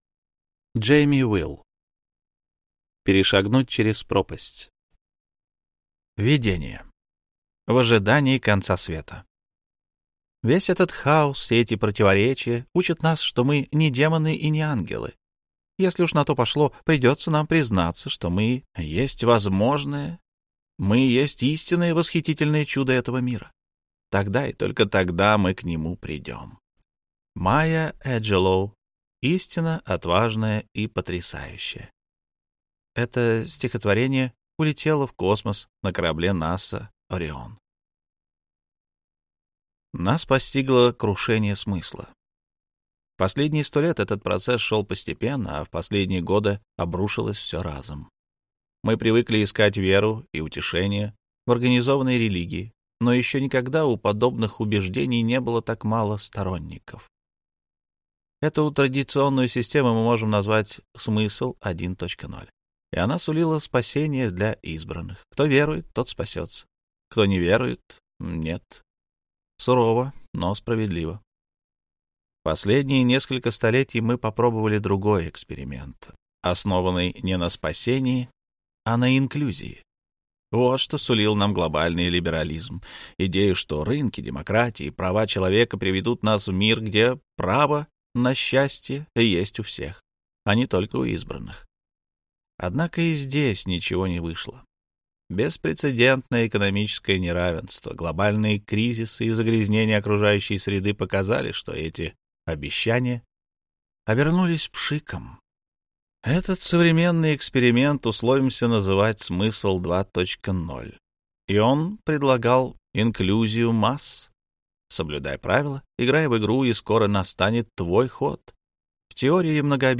Аудиокнига Перешагнуть через пропасть. Гедонистическая инженерия против уныния, одиночества и разобщенности | Библиотека аудиокниг